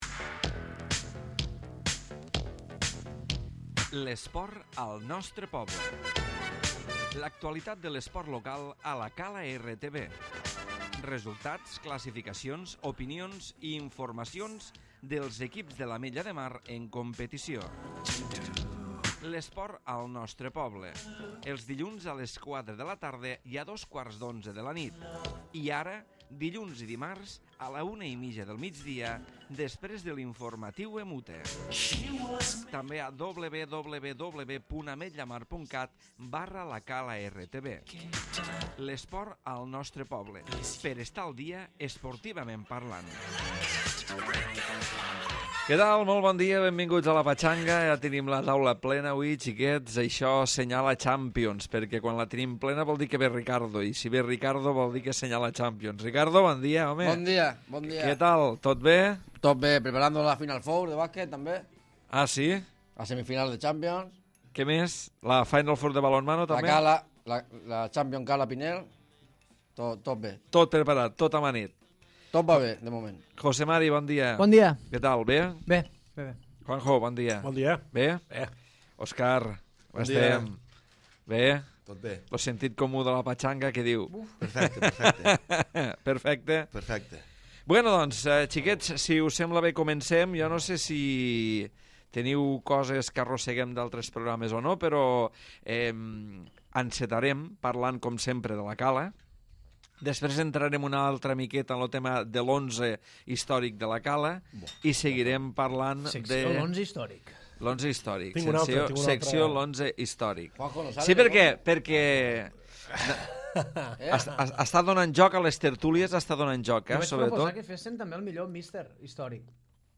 Programa tertúlia d'actualitat futbolística